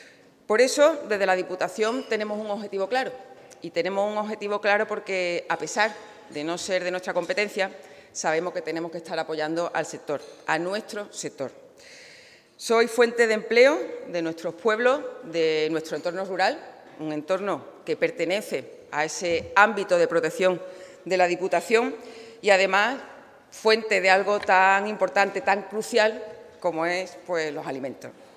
La presidenta de la Diputación, Almudena Martínez, ha participado en Cádiz en la Charla-coloquio del ciclo La Mirada Económica ‘¿Hay esperanzas para el sector primario en la UE?’, que ha organizado La Voz de Cádiz y en la que ha puesto de relevancia el compromiso de la entidad provincial con el Sector Primario.